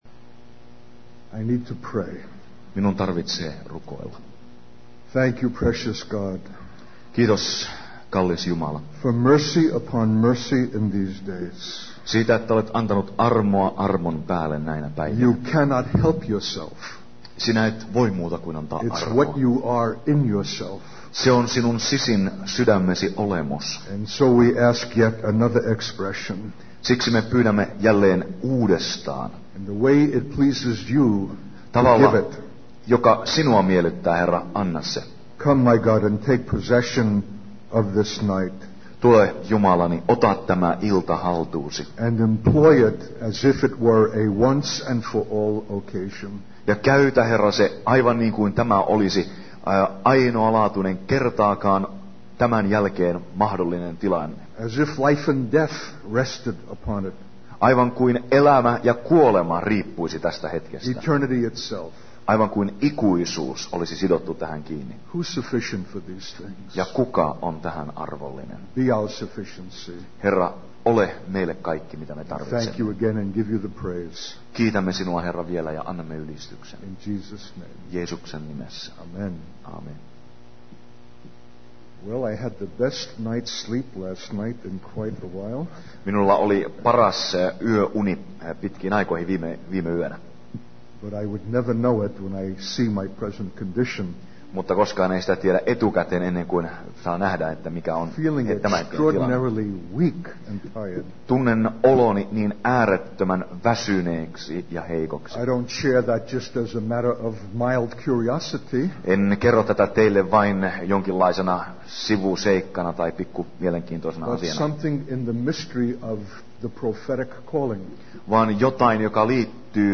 In this sermon, the speaker, who is a Jew from New York City, shares his personal journey of living in a remote location in North America. He explains that he was led by the word of the Lord to establish an end time teaching center to provide refuge for Jews in the United States during the time of Jacob's trouble. The speaker emphasizes the urgency of preparing for the coming calamity in Israel and encourages the audience to join a conference tour in Jerusalem to discuss this topic.